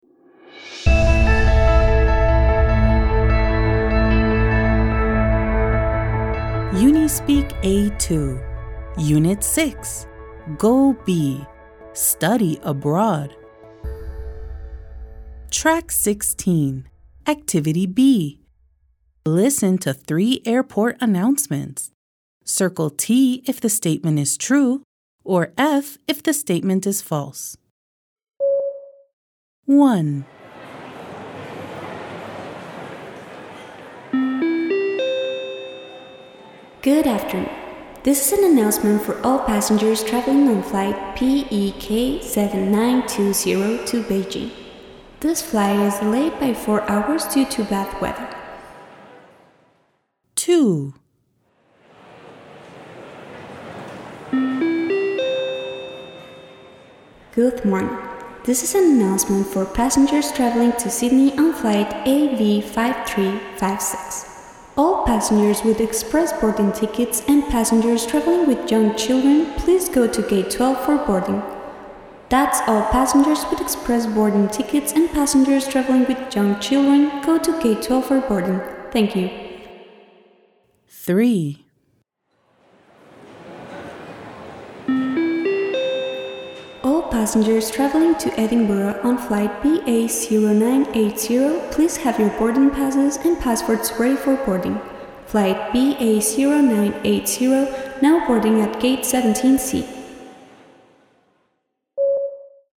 Activity B. Listen to three airport announcements. Circle T if the statement is true, or F if the statement is false.